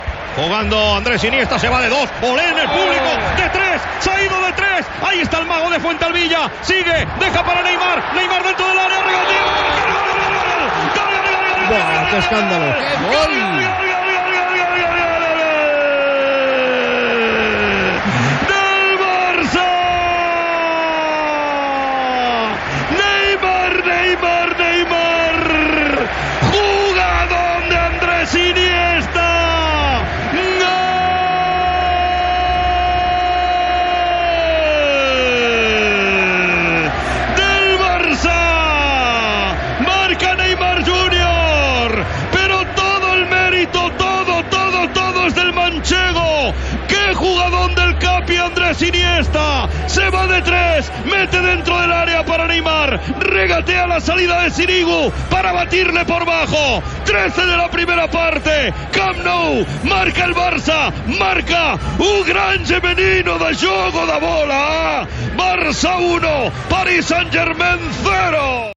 Transmissió del partit de quarts de final de la Copa d'Europa de Futbol masculí entre el Futbol Club Barcelona i el Paris Saint Germain.
Narració del gol del primer gol de Neymar.